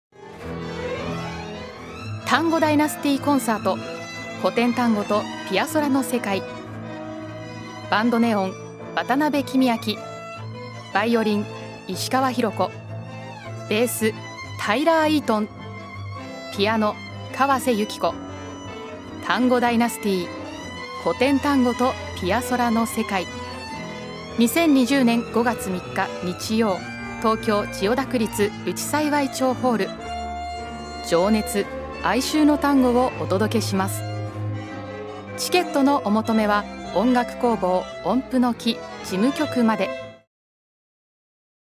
ナチュラルな英語発音は、館内案内などの英語バージョンにも
Voice sample 2